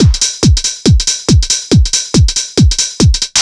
NRG 4 On The Floor 046.wav